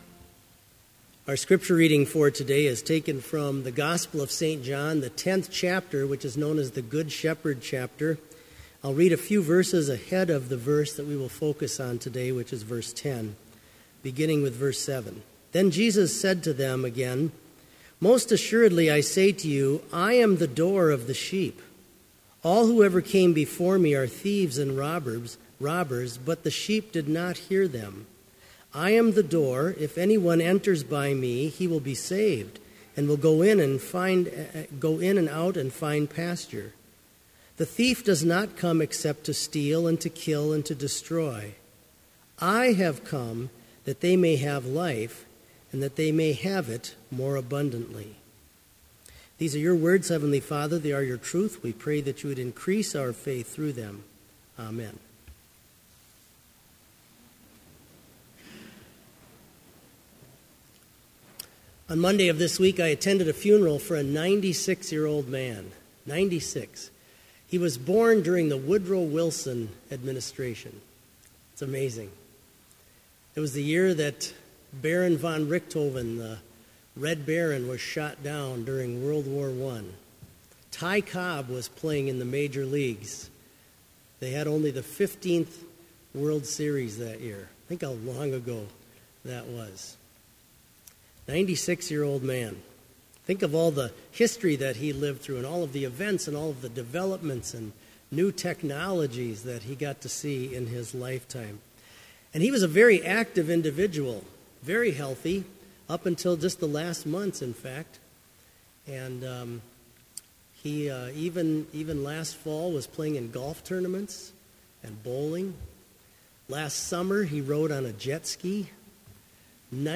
Complete service audio for Summer Chapel - July 22, 2015